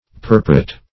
Purpurate \Pur"pu*rate\, a.
purpurate.mp3